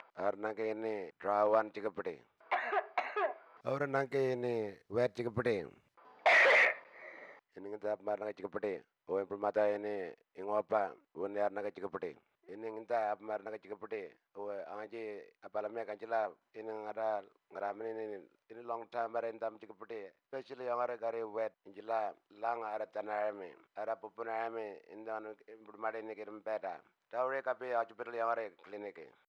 Cough Audio – Tiwi
20048_dry-&-wet-cough-tiwi-finalb.wav